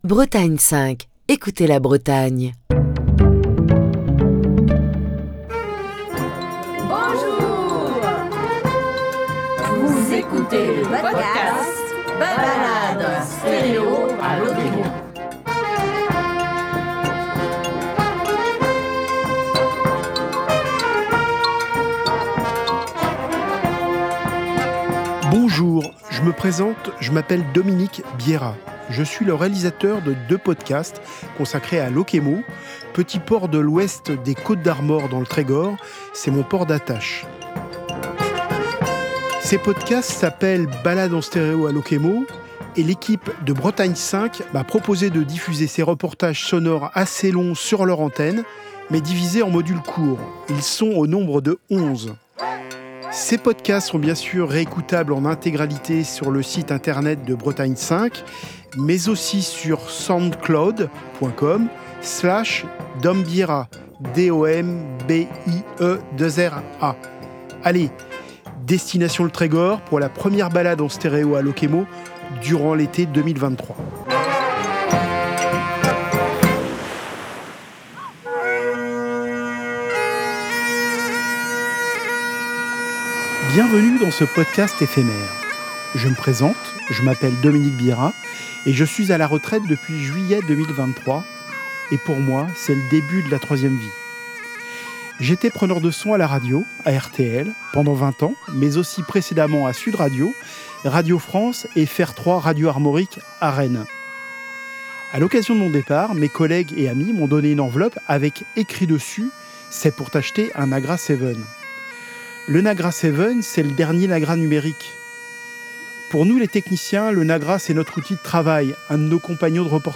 Émission du 7 février 2025.